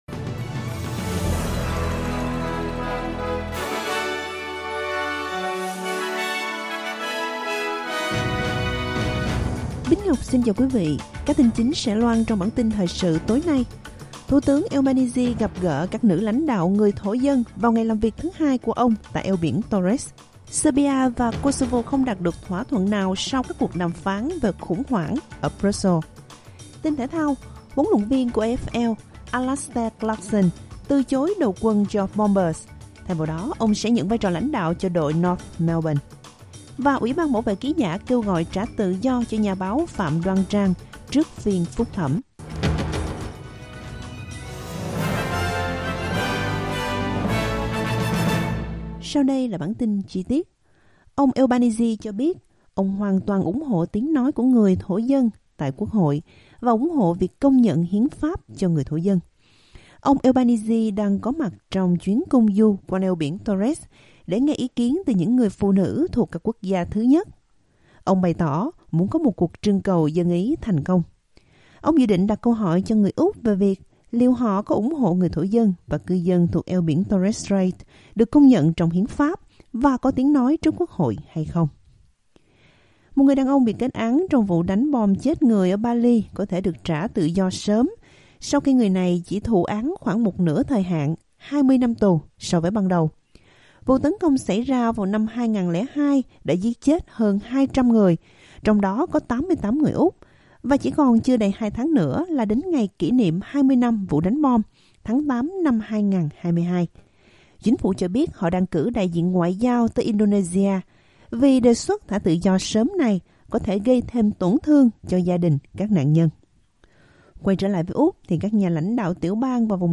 Vietnamese news bulletin Source: Getty
vietnamese-news1-1908.mp3